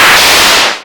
RADIOFX 10-L.wav